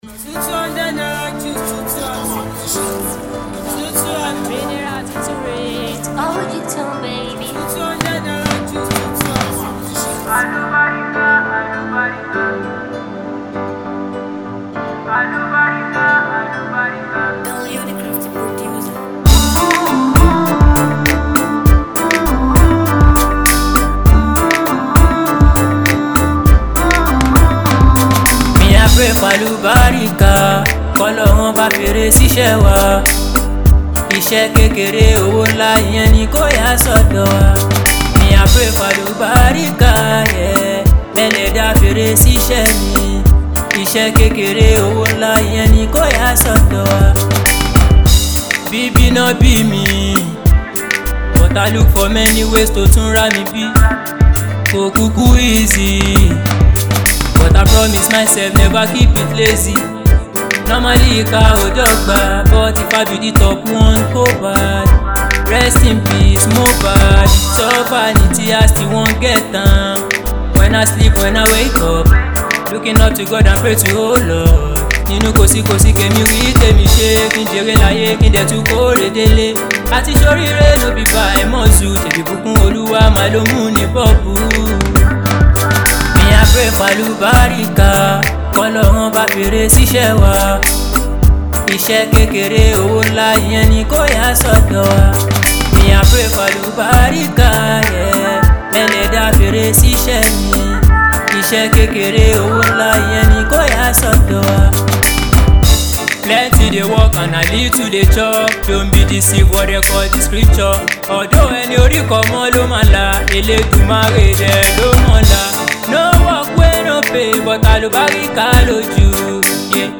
soulful and uplifting
Genre: Afro-Fusion / Soul